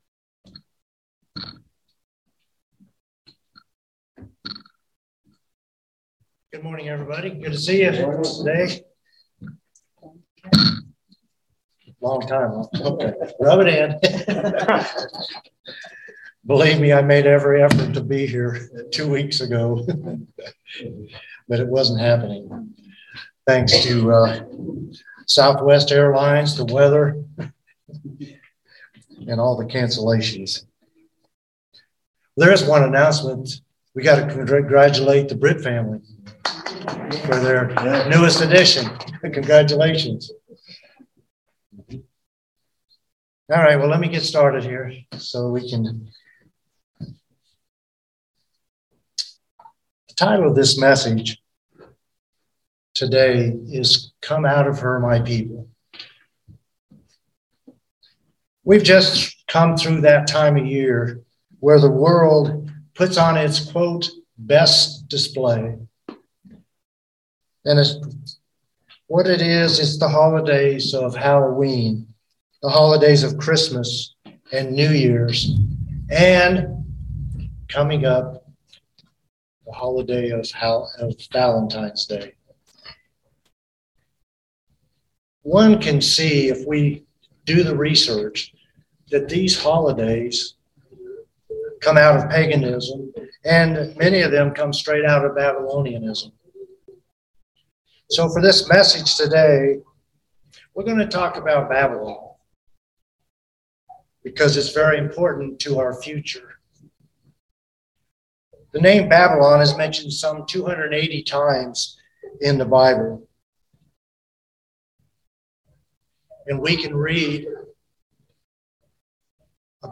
Sermons
Given in Central Georgia Columbus, GA